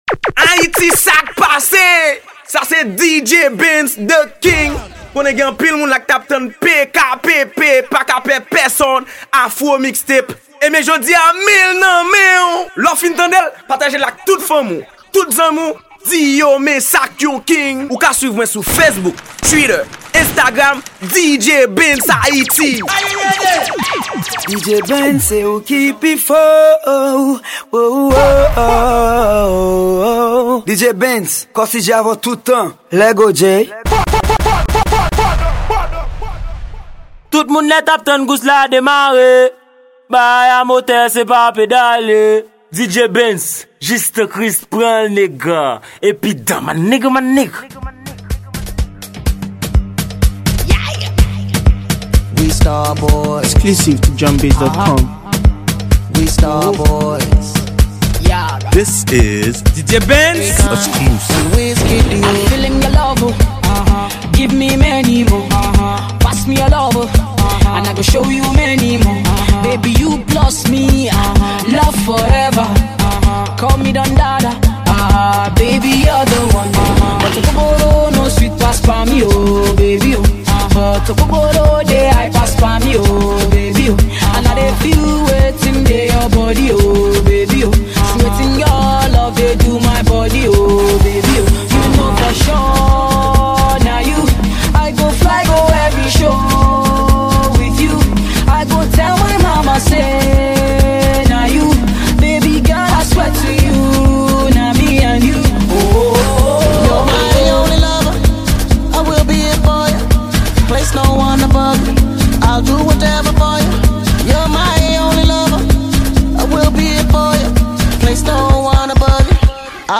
Genre: MIXTAPE.